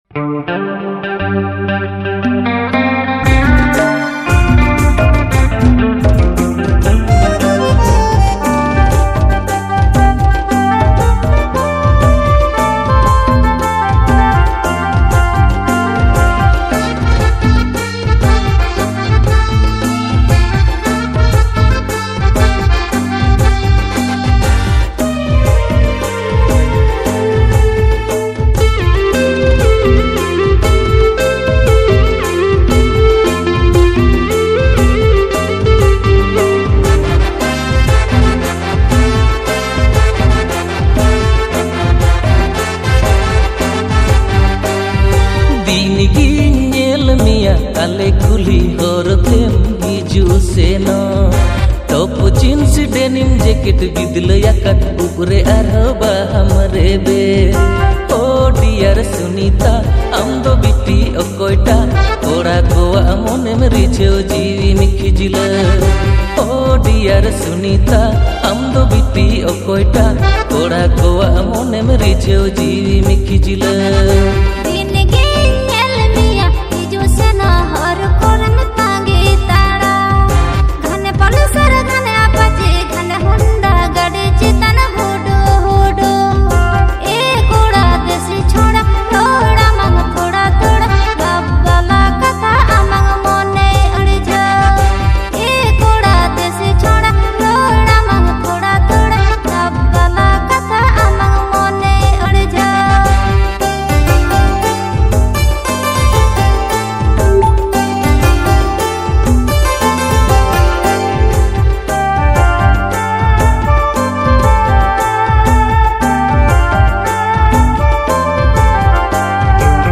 Santali song
• Male Artist